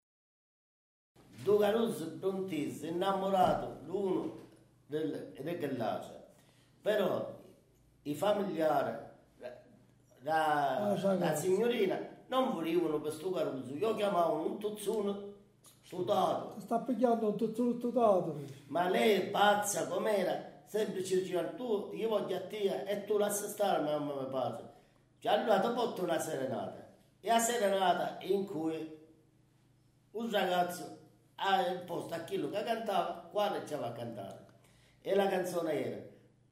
SERENATE E FILASTROCCHE
Intro Serenata